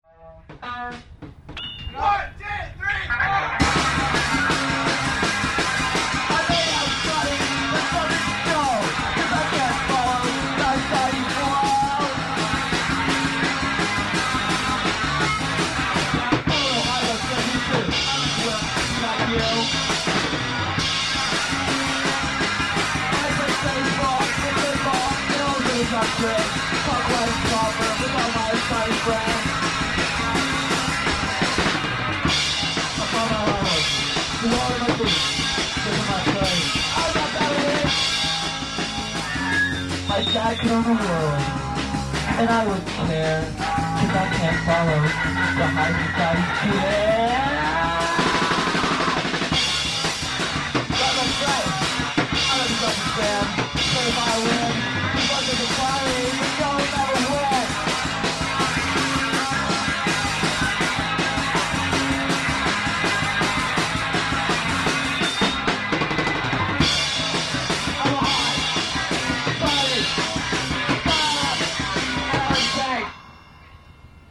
Live!
on drums